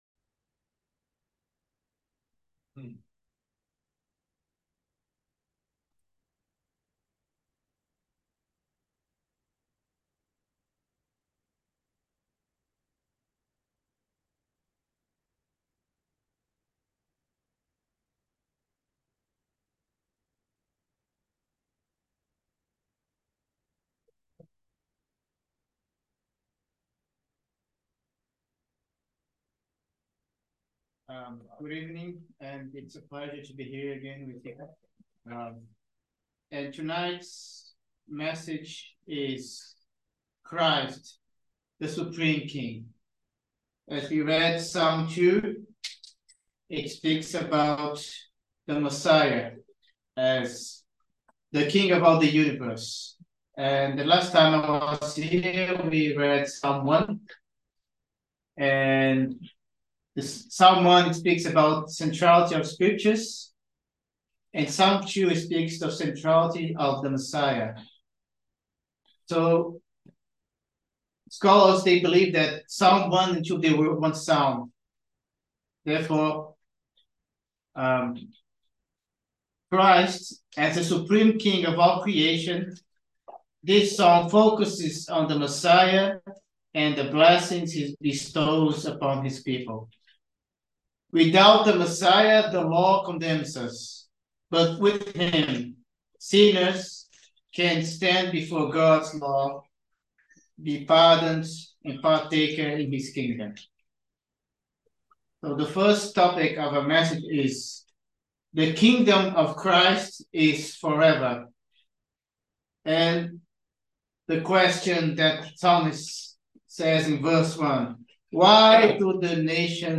Passage: Romans 1:1-4; Psalm 2 Service Type: Sunday Evening Service Christ the Supreme King « Wednesday